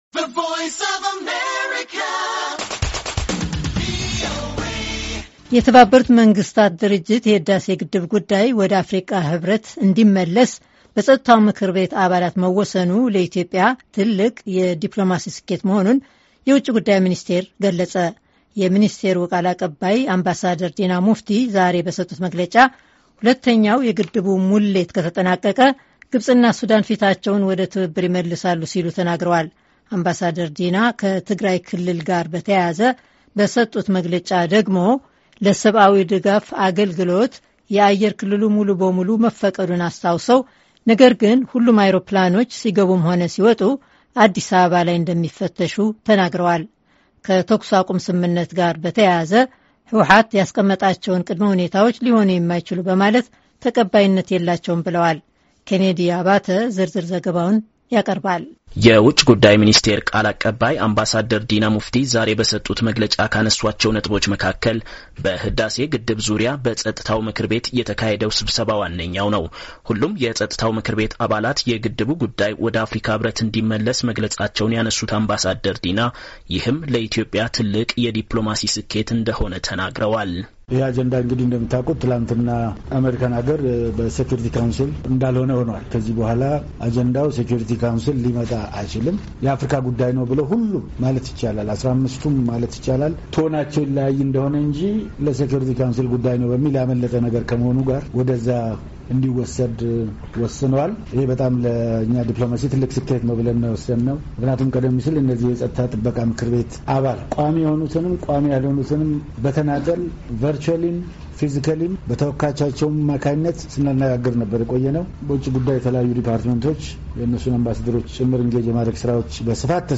የተመድ የሕዳሴ ግድብ ጉዳይ ወደ አፍሪካ ሕብረት እንዲመለስ በጸጥታው ምክር ቤት አባላት መወሰኑ ለኢትዮጵያ ትልቅ የዲፕሎማሲ ስኬት መሆኑን የውጭ ጉዳይ ሚኒስቴር ገለጸ። የሚኒስቴሩ ቃል አቀባይ አምባሳደር ዲና ሙፍቲ ዛሬ በሰጡት መግለጫ፣ ሁለተኛው የግድቡ ሙሌት ከተጠናቀቀ፣ ግብጽና ሱዳን ፊታቸውን ወደ ትብብር ይመልሳሉ ሲሉ ተናግረዋል፡፡...